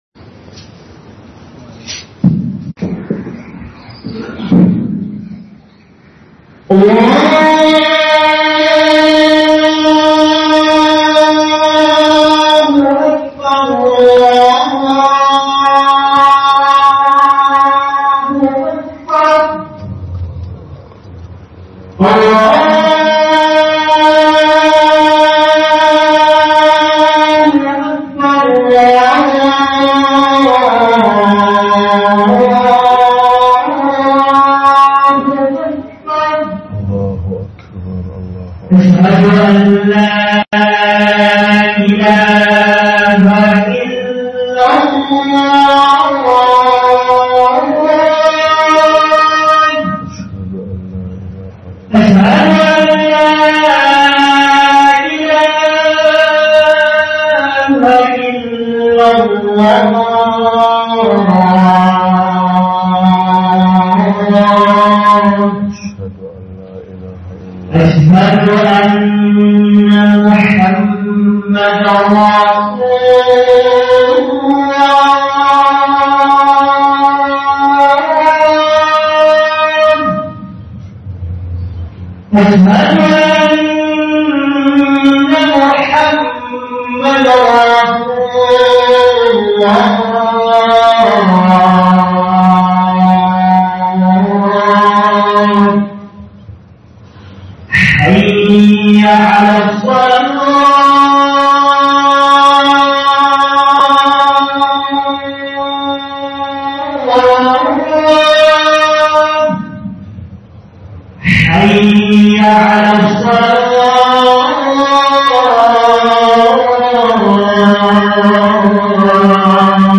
Addini Dabi'u na Kwarai ne - Huduba